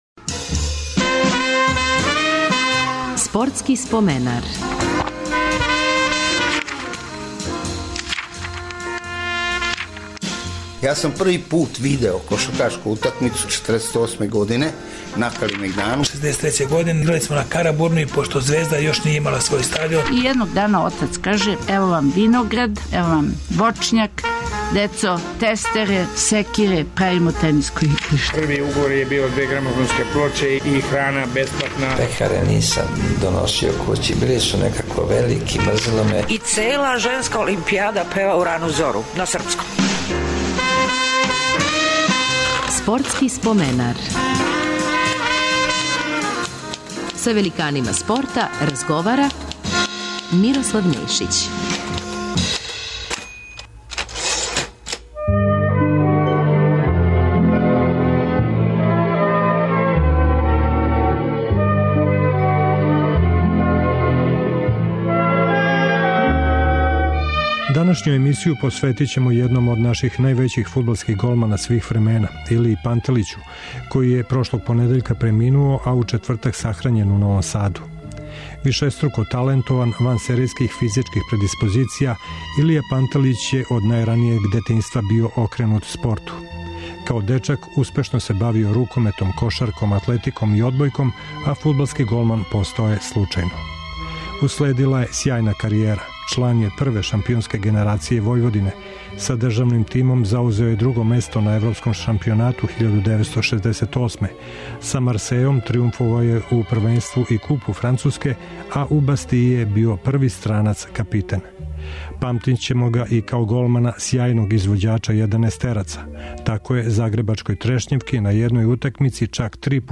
Емисију ћемо посветити недавно преминулом Илији Пантелићу, једном од наших најбољих фудбалских голмана свих времена. Репризираћемо разговор својевремено снимљен за Спортски споменар.